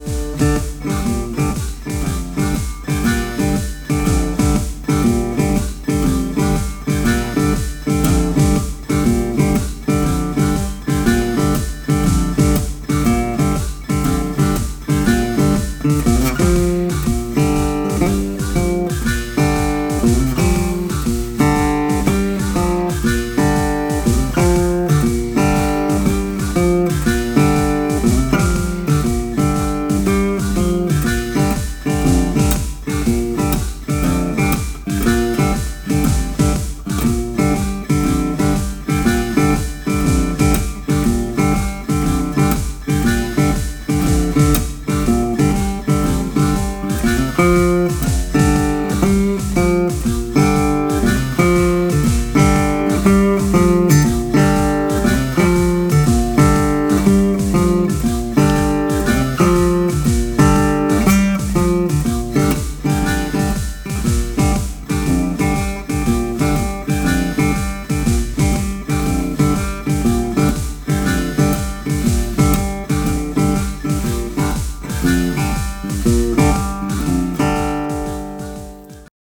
Blues Gitarre mit Pulsierendem Shuffle Loop, epic.